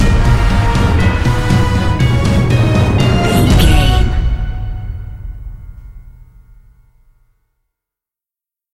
Epic / Action
Aeolian/Minor
E♭
driving drum beat